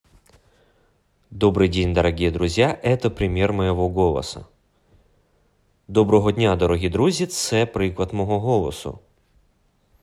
голос.m4a
Чоловіча
Обладаю красивым тембром голоса, хорошей дикцией с соблюдением интонации.